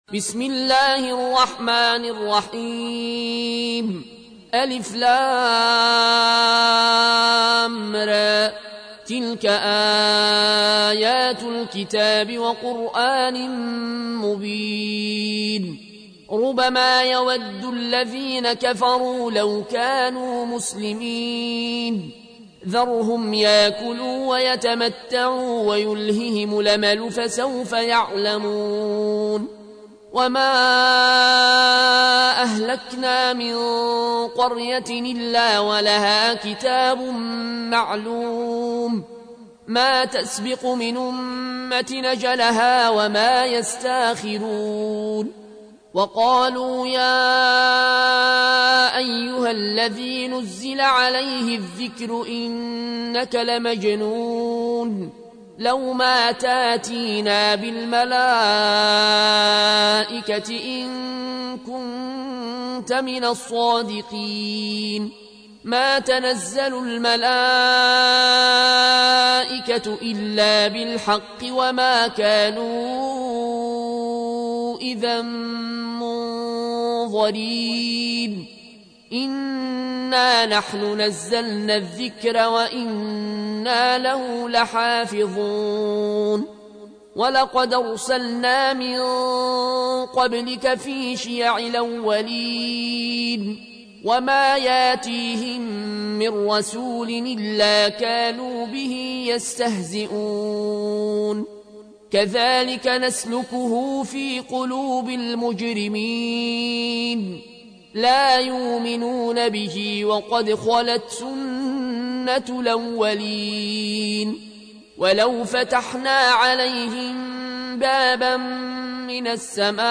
تحميل : 15. سورة الحجر / القارئ العيون الكوشي / القرآن الكريم / موقع يا حسين